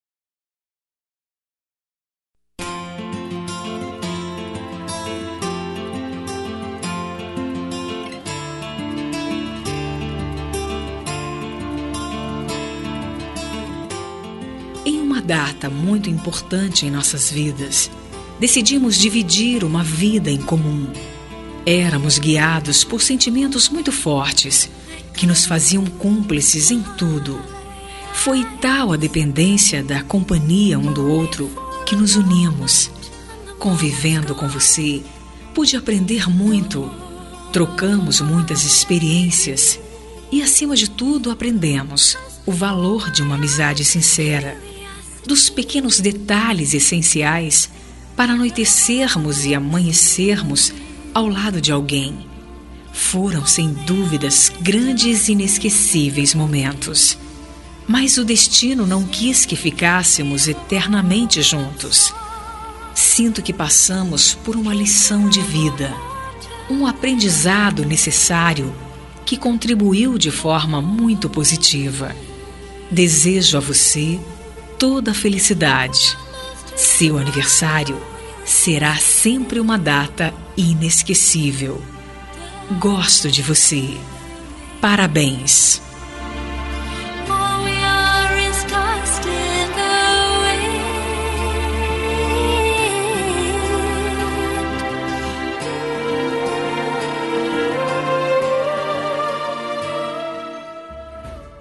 Telemensagem de Aniversário de Ex – Voz Feminina – Cód: 1368 – Ex. Marido
Telemensagem Aniversário Ex Marido -1137.mp3